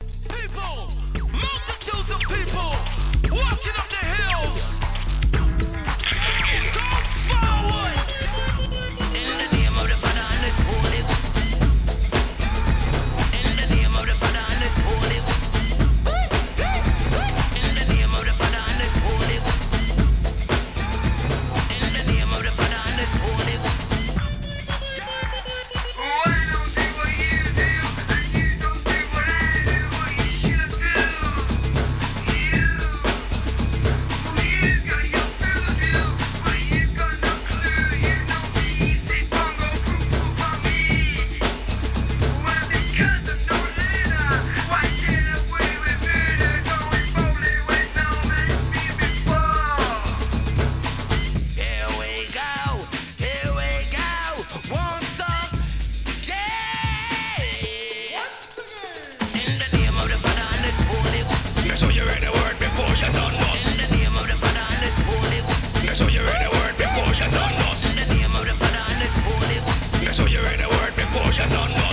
HIP HOP/R&B
シタールの音色のイントロから始まるアッパーなBEATにラガMC!!